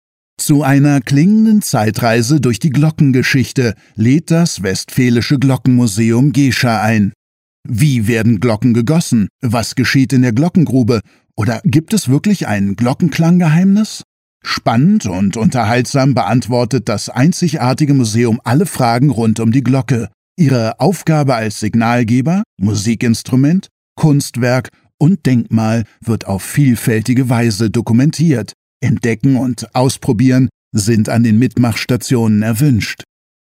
audio-gockenguss-gescher.mp3